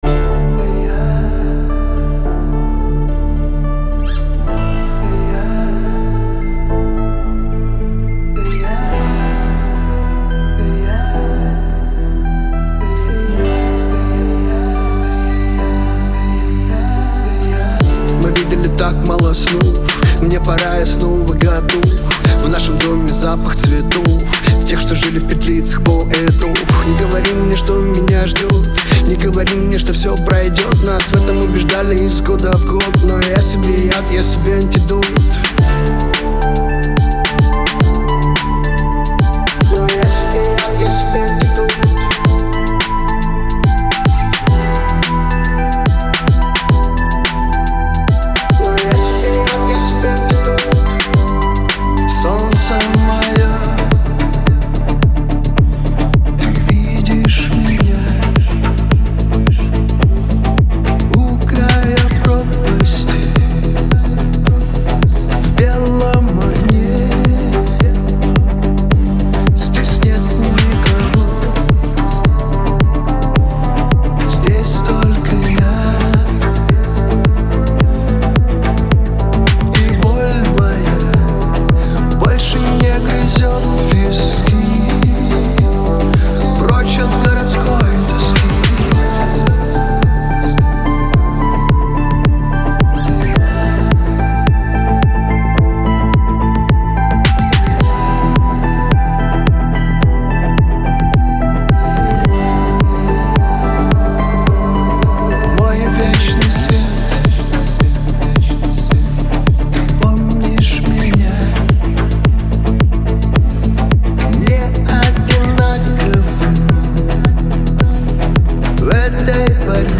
Категории: Русские песни, Поп.